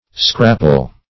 Scrapple \Scrap"ple\, n. [Dim. of scrap.]